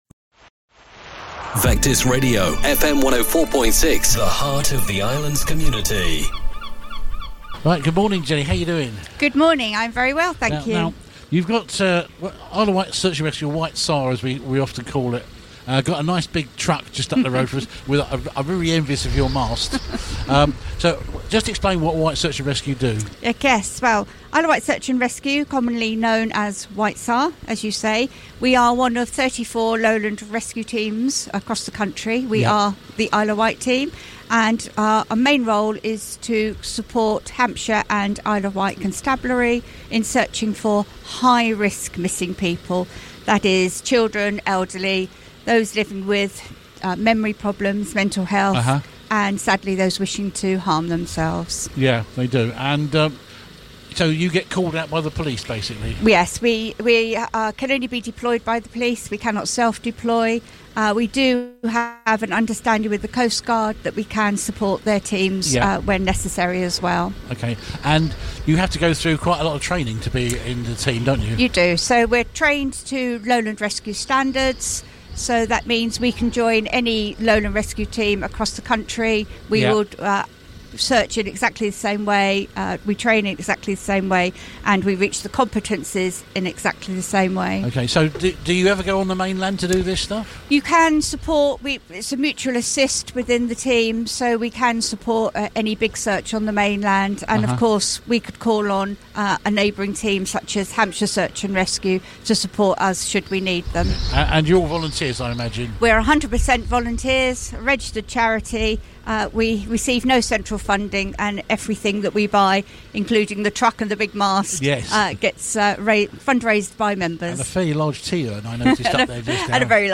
at the Chale Show 2025.